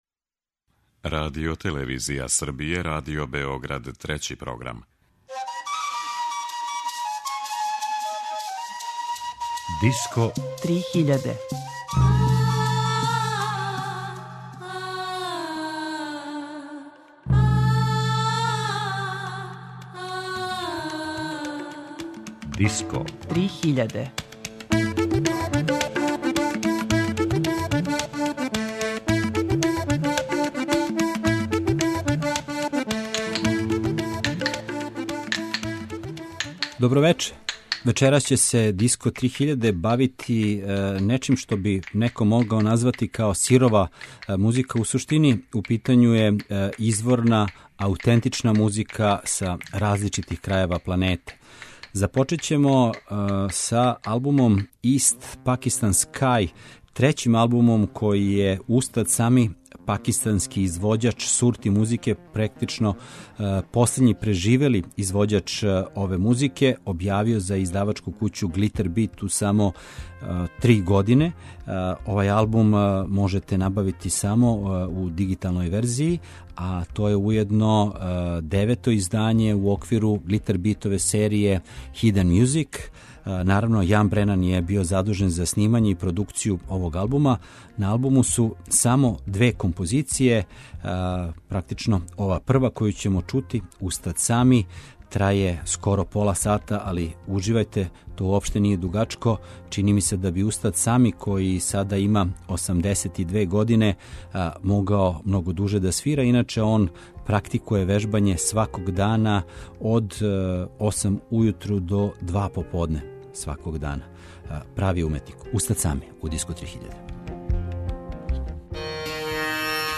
Аутентично, чисто и сирово
У вечерашњој емисији представићемо три нова албума аутора са различитих страна света, који аутентичне, сирове звуке претварају у уметност.